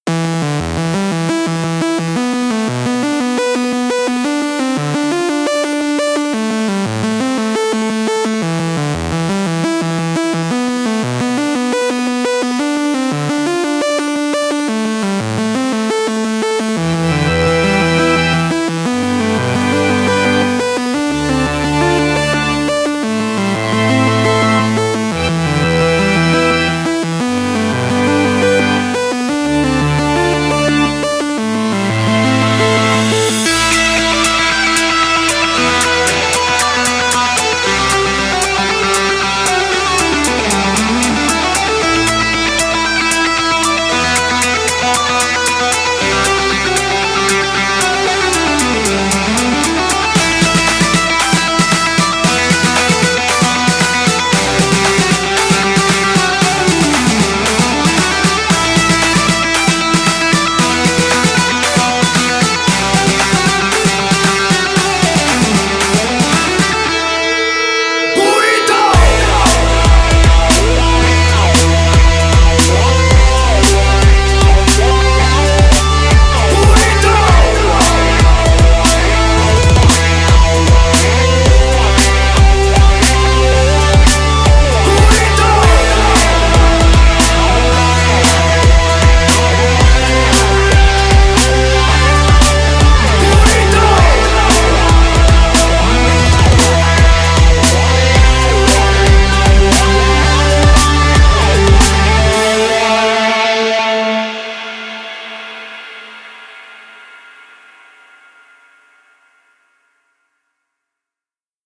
Жанр: Жанры / Поп-музыка